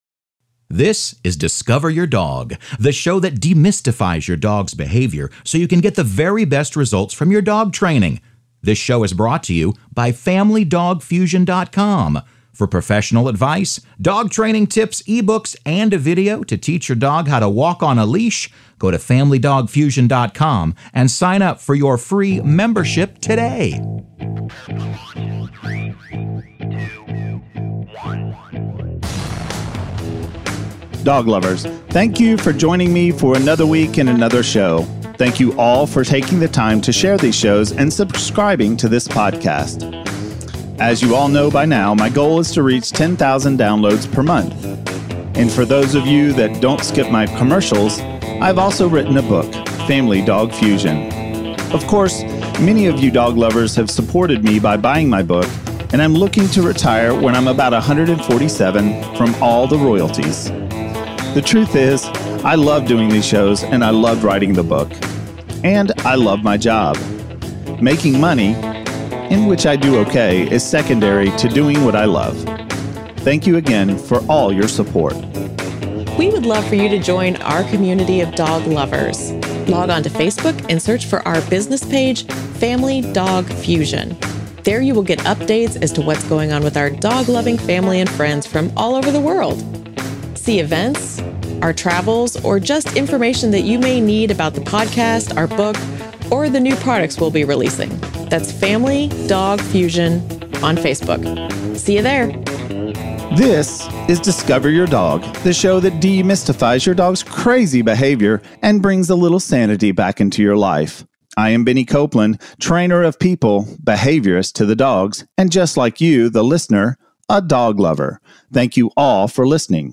Ep 205 A Reading From Family Dog Fusion - FamilyDogFusion